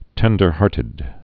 (tĕndər-härtĭd)